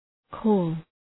Προφορά
{kɔ:l}